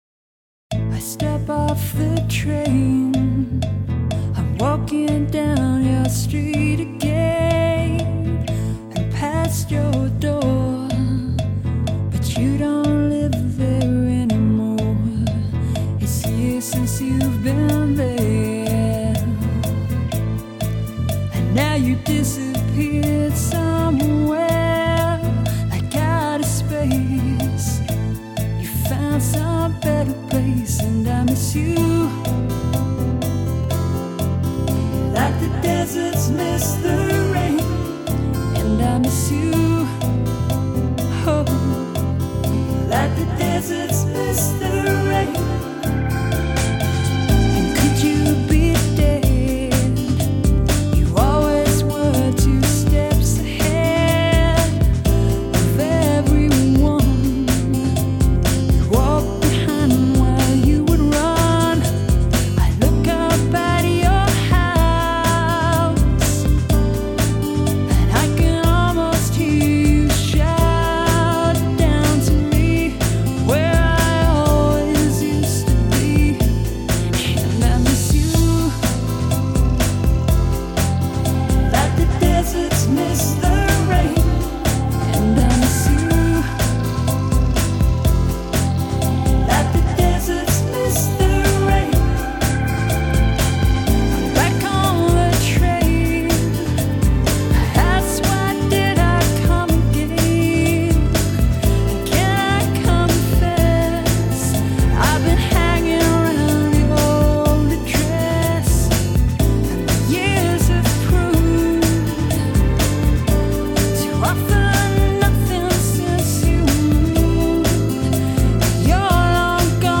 An evocative song